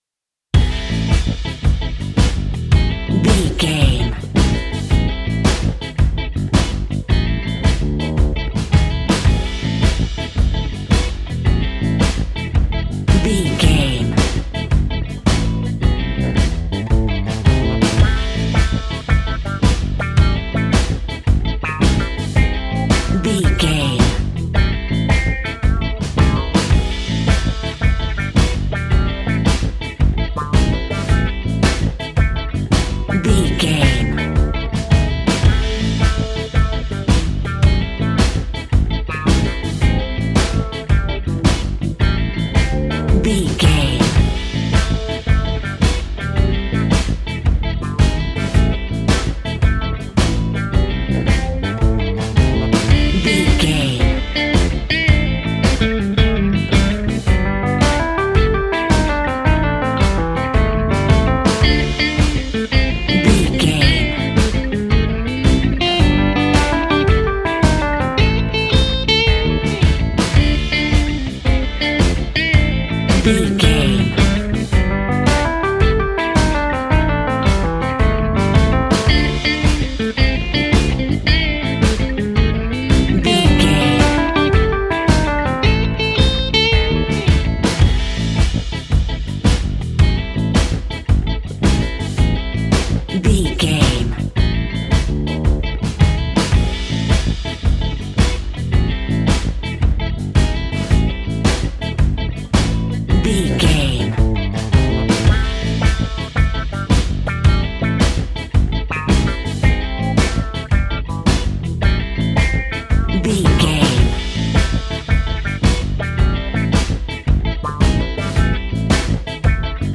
Aeolian/Minor
funky
uplifting
bass guitar
electric guitar
organ
percussion
drums
saxophone
groovy